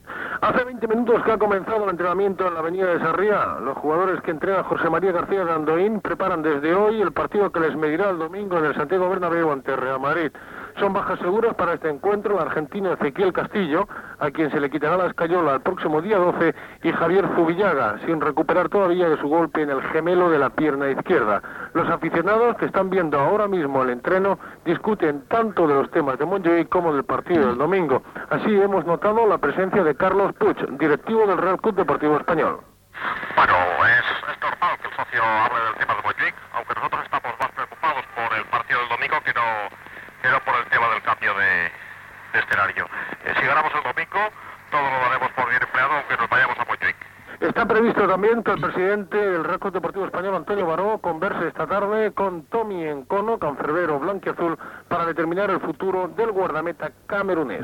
Informació, des del camp de l'Avinguda de Sarrià de Barcelona, de l'entrenament del Real Club Deportiu Espanyol masculí
Esportiu